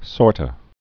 (sôrtə)